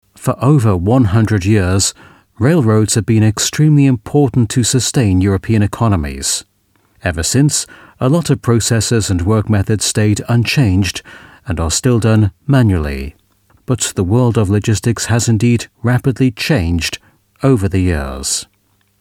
Native Speaker
Englisch (UK)
Explainer Videos